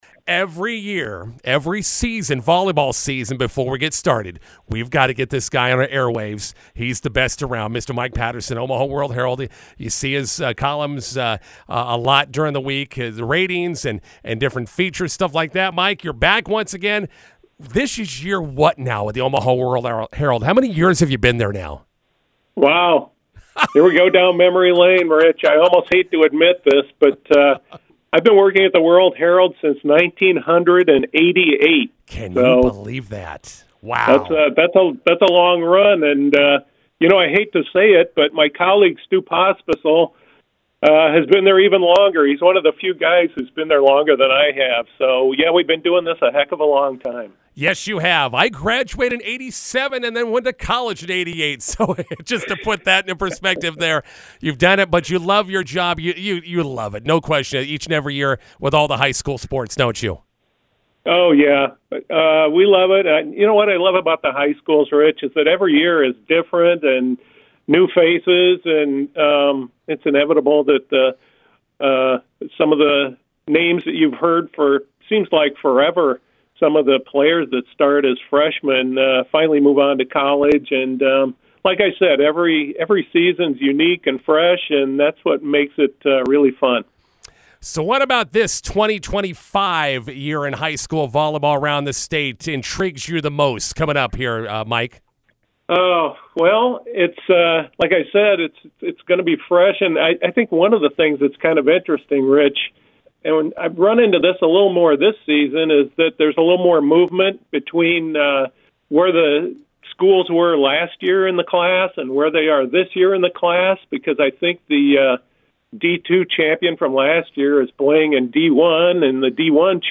INTERVIEW: Nebraska high school volleyball is back.